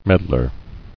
[med·lar]